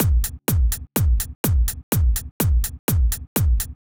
Drumloop 125bpm 05-C.wav